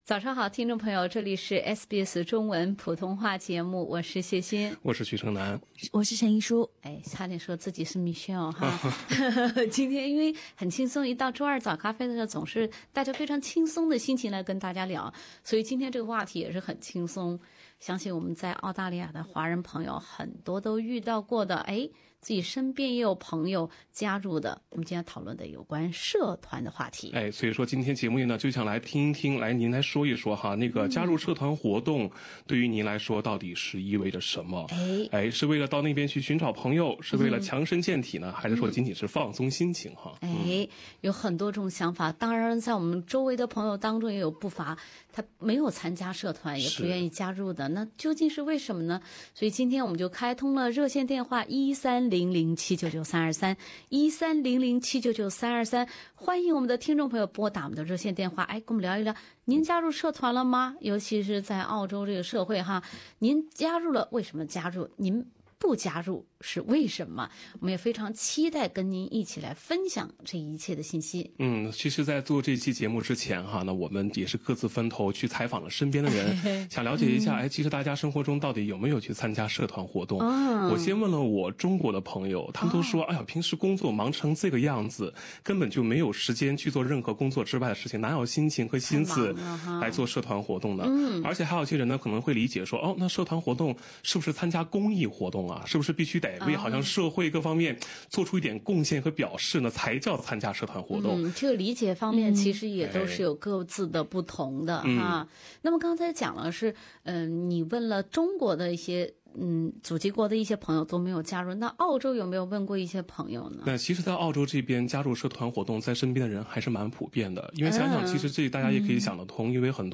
本期《早咖啡》节目，听众与主持人畅谈在澳大利亚的社团生活。